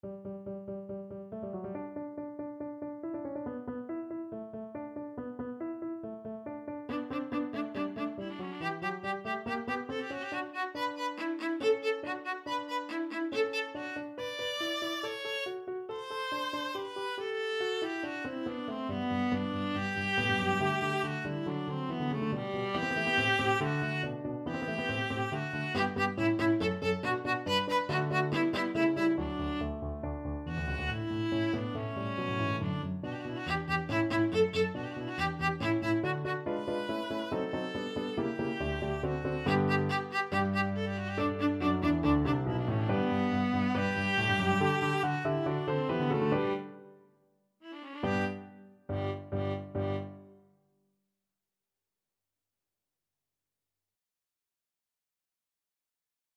Free Sheet music for Viola
Viola
4/4 (View more 4/4 Music)
= 140 Allegro (View more music marked Allegro)
G major (Sounding Pitch) (View more G major Music for Viola )
G4-D6
Classical (View more Classical Viola Music)